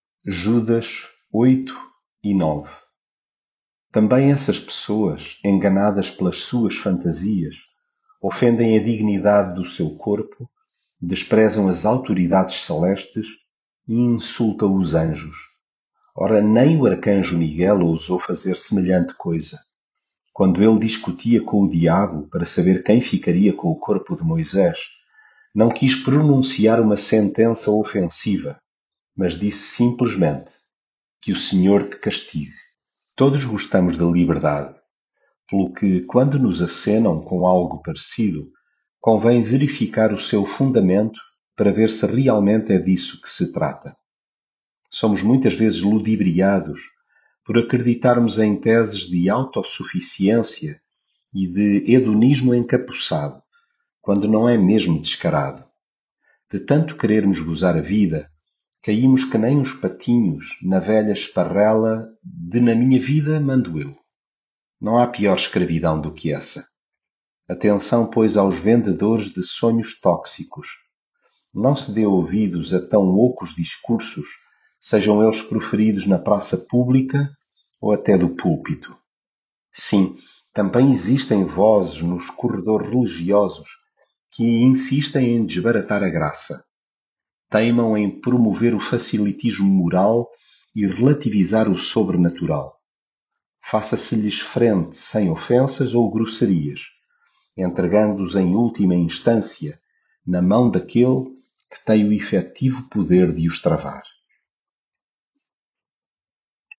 devocional Judas
leitura bíblica